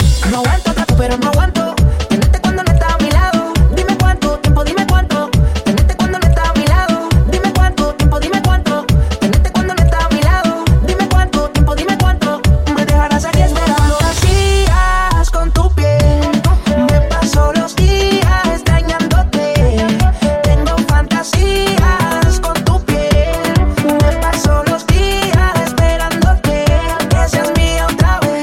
Genere: latin pop, latin urban, reggaeton, remix